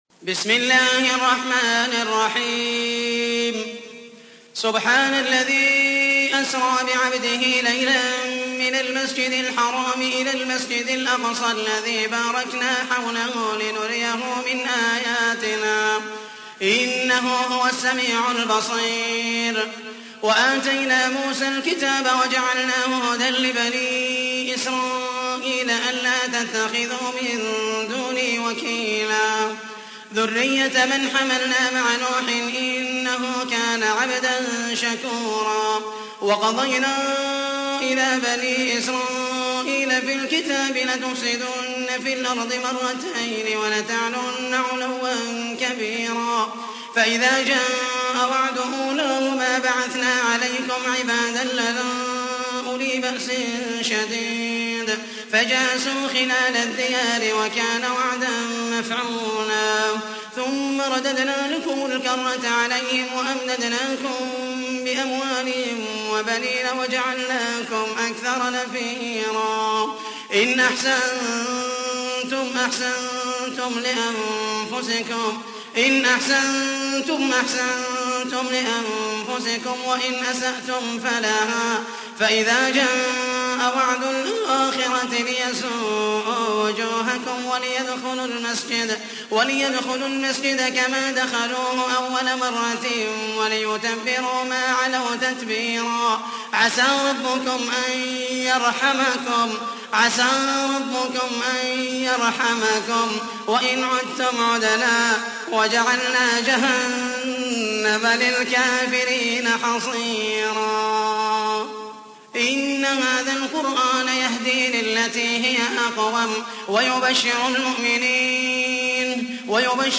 تحميل : 17. سورة الإسراء / القارئ محمد المحيسني / القرآن الكريم / موقع يا حسين